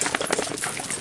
PixelPerfectionCE/assets/minecraft/sounds/mob/wolf/shake.ogg at mc116